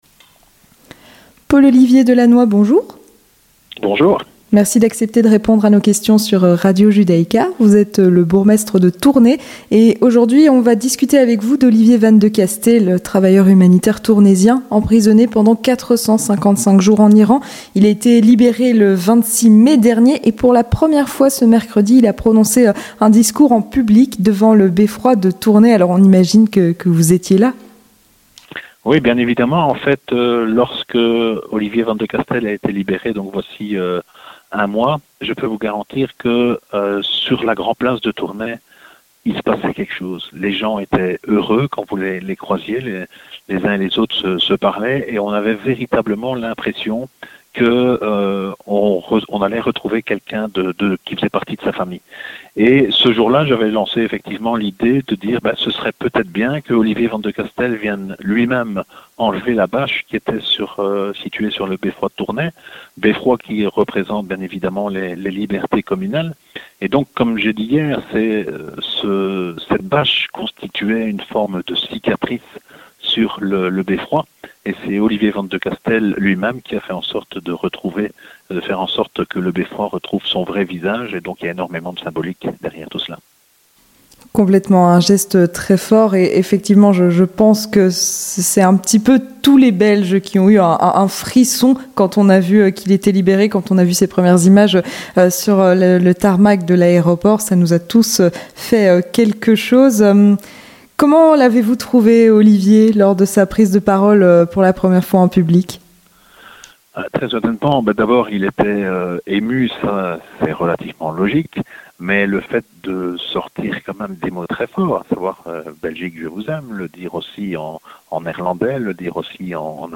Entretien du 18h - Le premier discours en public d'Olivier Vandecasteele
Avec Paul-Olivier Delannois, bourgmestre de Tournai